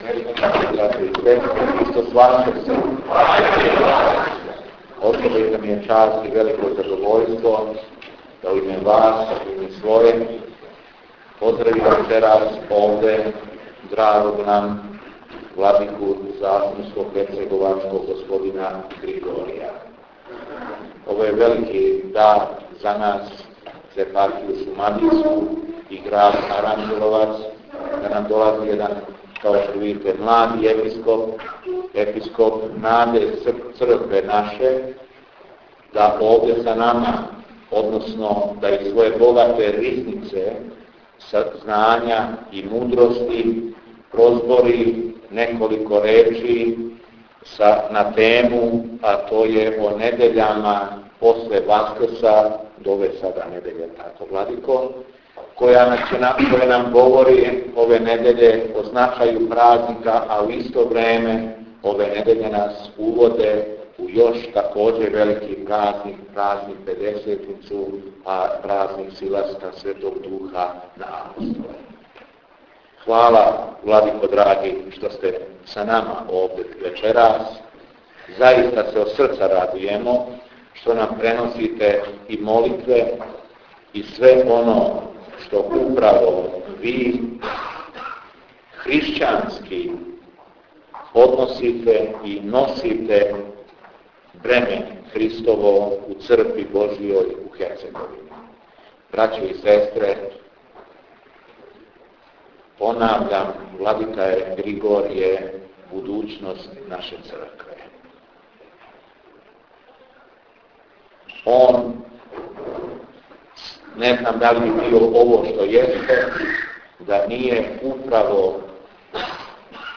ПРЕДАВАЊЕ ВЛАДИКЕ ГРИГОРИЈА У АРАНЂЕЛОВЦУ - Епархија Шумадијска